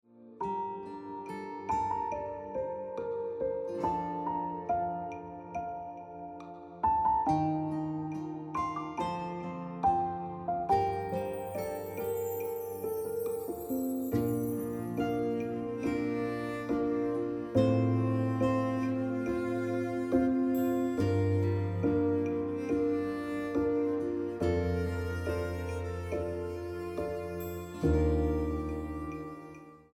Best Ringtones, Piano Music Ringtone